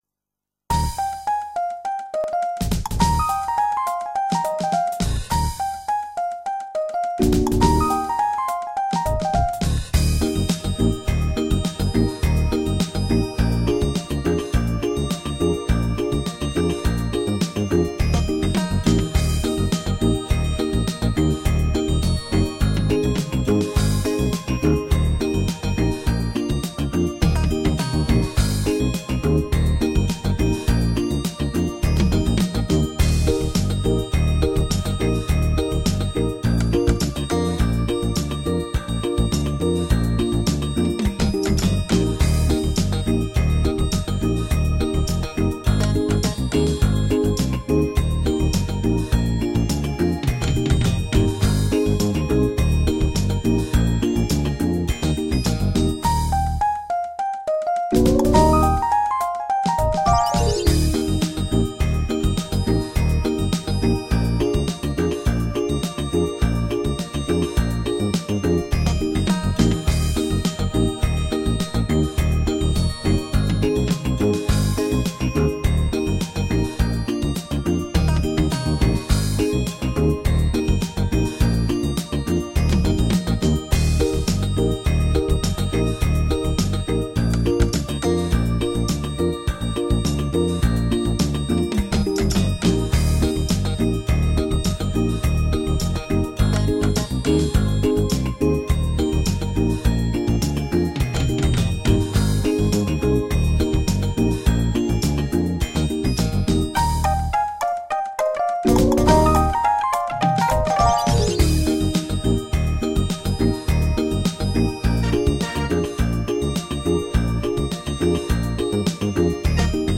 Przyjd-cie-do-mnie--wersja-instrumentalna.mp3